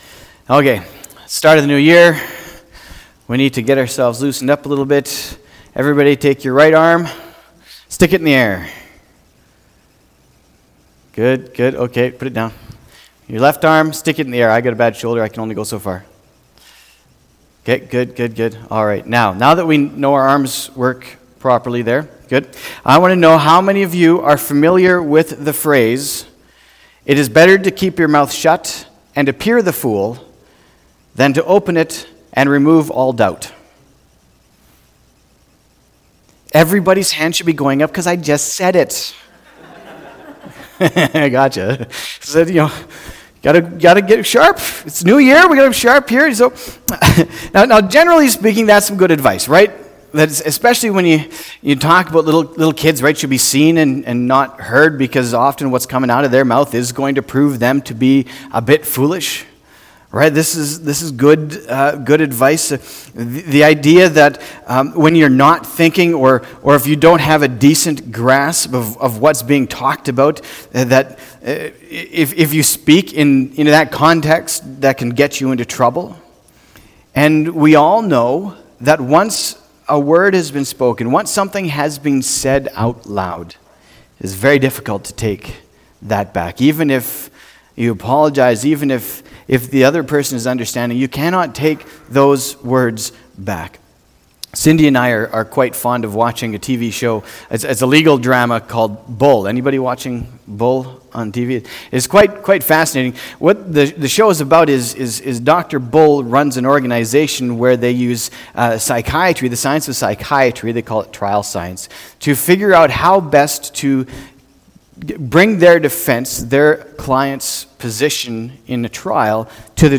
january-5-2020-sermon.mp3